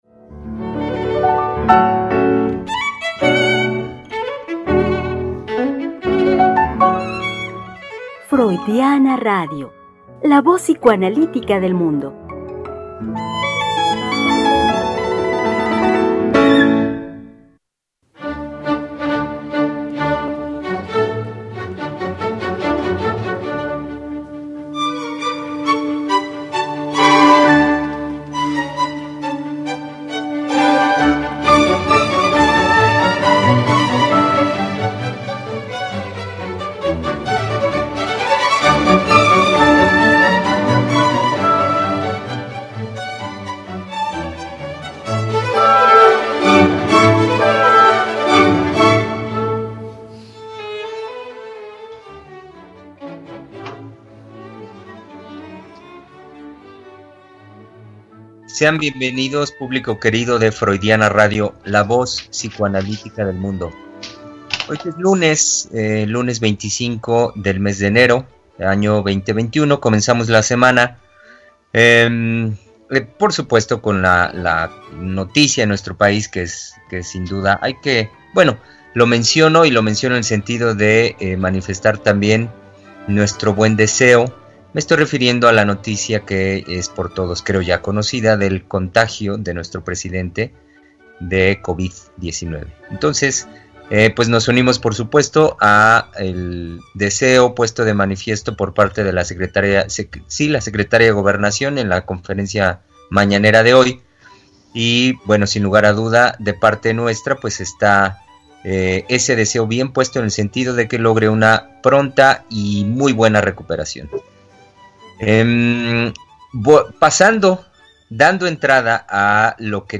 Tres Mujeres Psicoanalistas Hablando de la Vida Cotidiana.
Conversación con las psicoanalistas